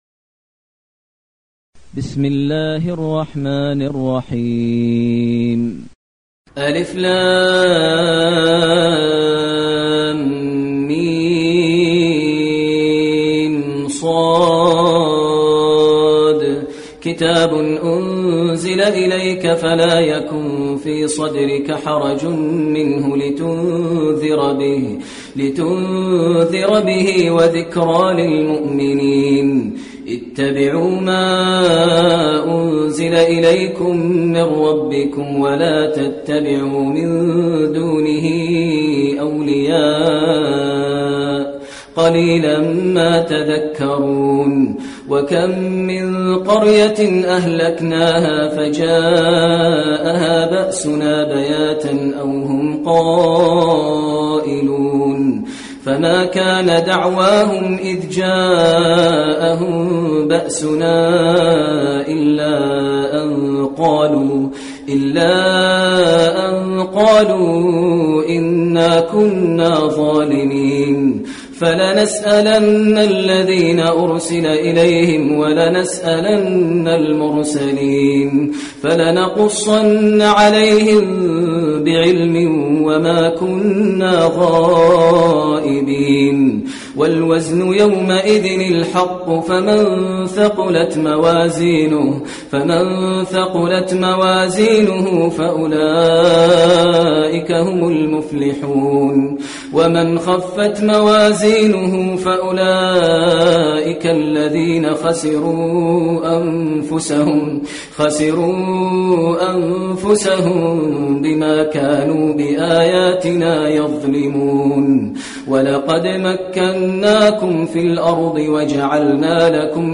المكان: المسجد النبوي الأعراف